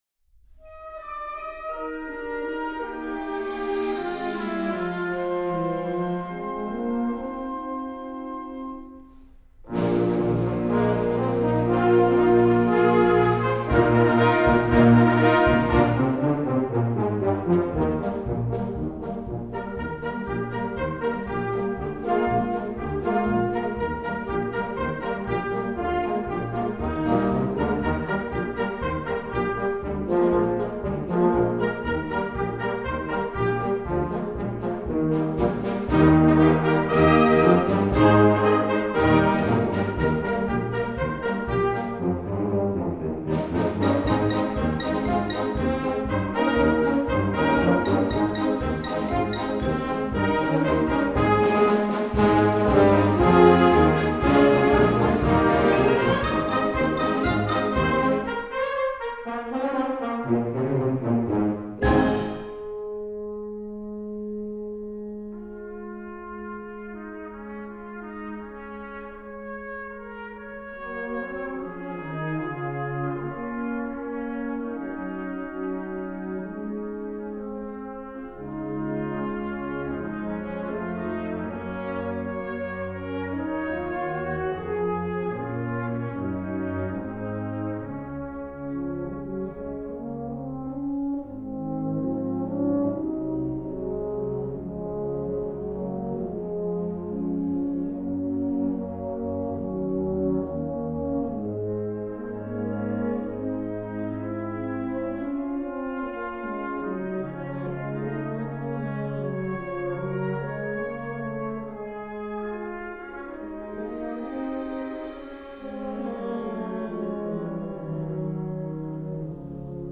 Partitions pour ensemble flexible, 5-voix + percussion.